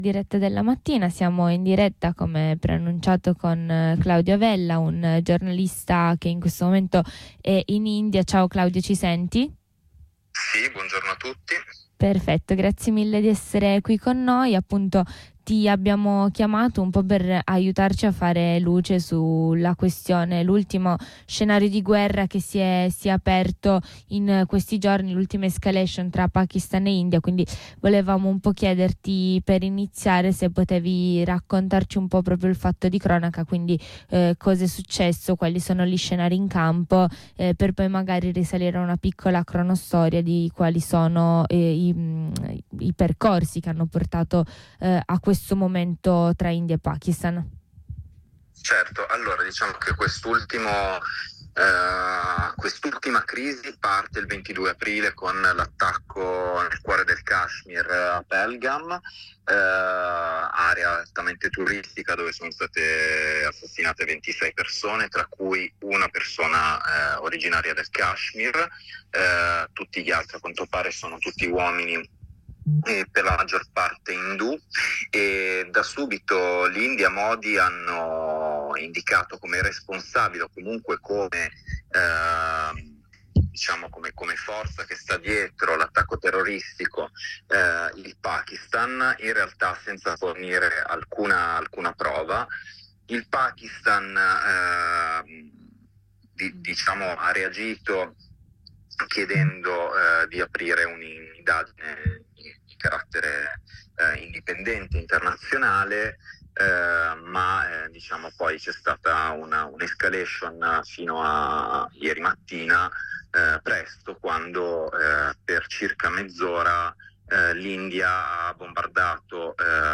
Ai nostri microfoni